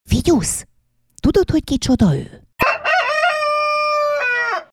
Kukurikú, ki vagyok én?
figyu-kukuriku.mp3